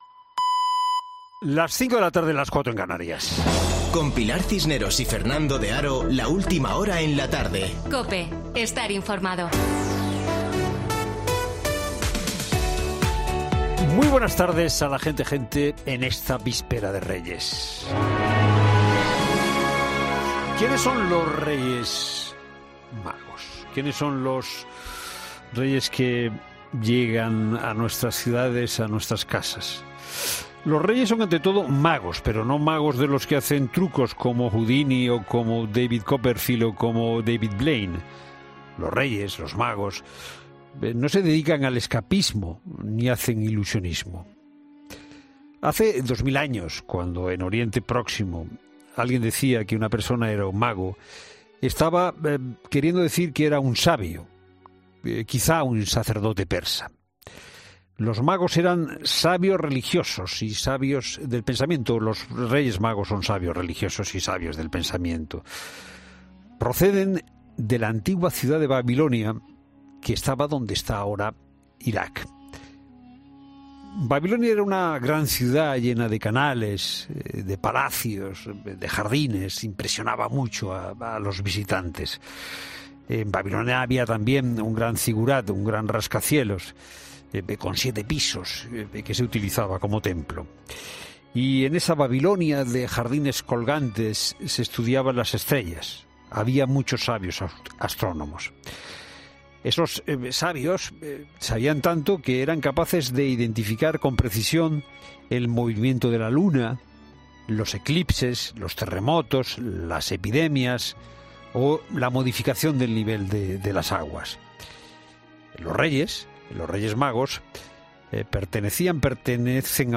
Boletín de noticias COPE del 5 de enero de 2022 a las 17:00 horas